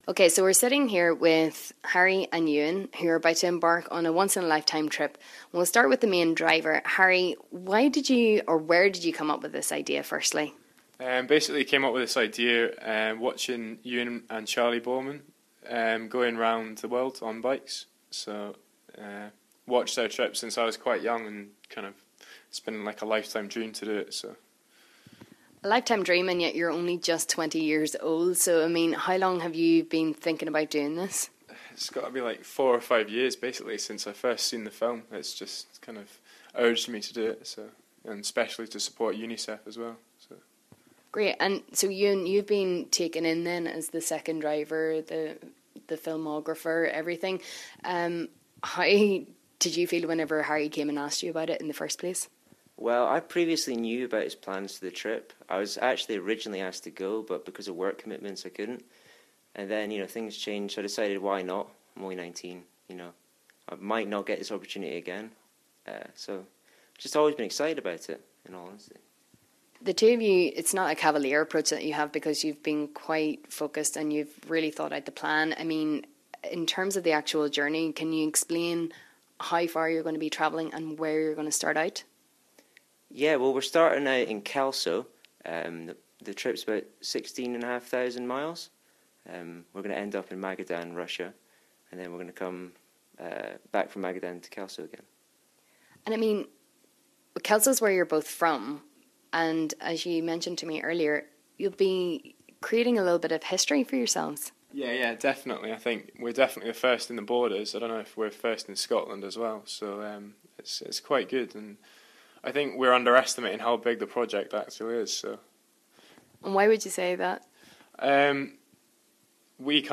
Kelso Fundraisers Full Interview with Radio Borders